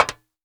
METAL 1A.WAV